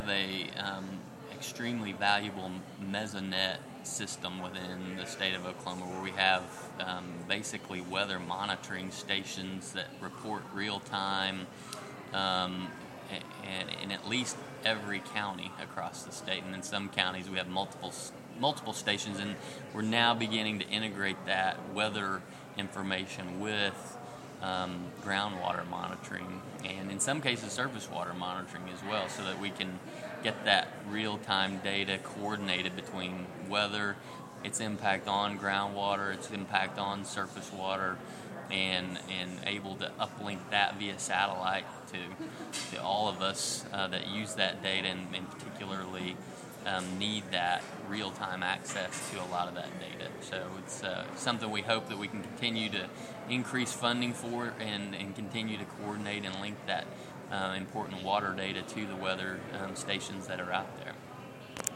JD Strong Oklahoma Director of Division of Water Resources discusses the state's "mesonet" weather, groundwater, and surface water data during ICWP/WSWC meeting in Washington, DC 3/16/12